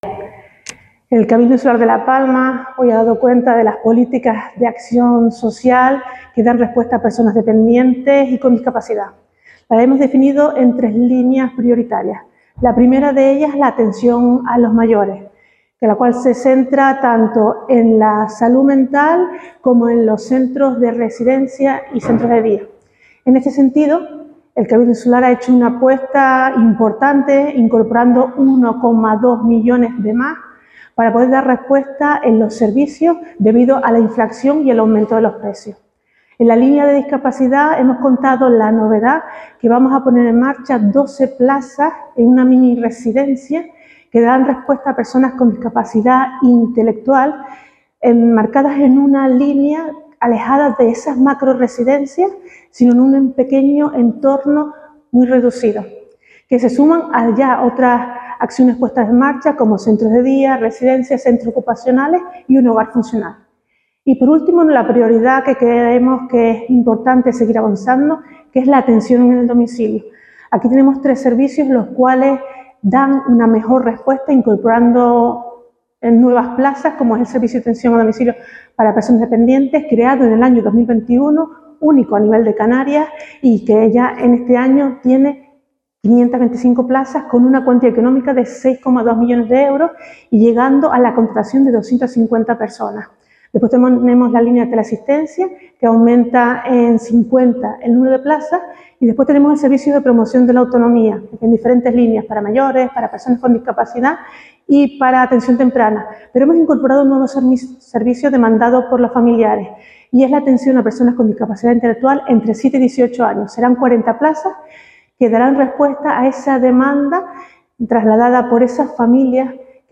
Declaraciones audio Nieves Hernández balance.mp3